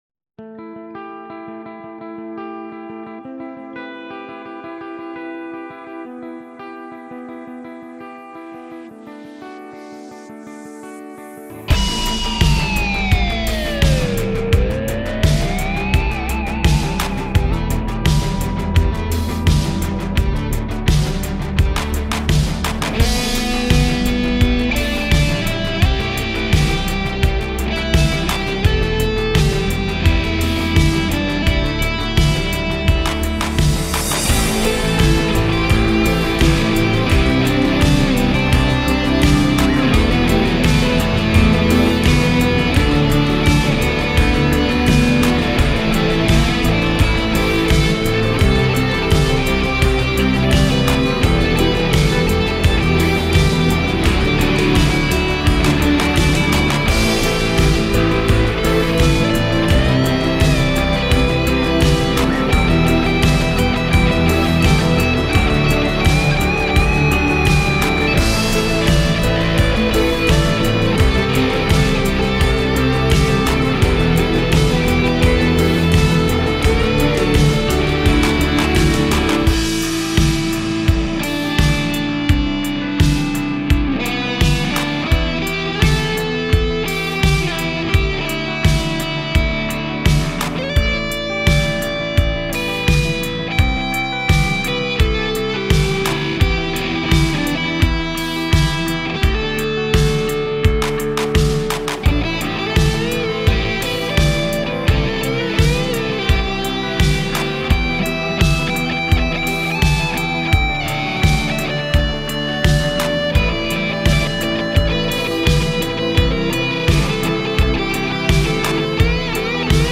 Hi, hab mich mal spontan von dem backingtrack zu einer Improvisation verleiten lassen.
Man verzeihe mir die Spielfehler und die späte Teilnahme. War doch etwas holprig und ob das alles richtig ist?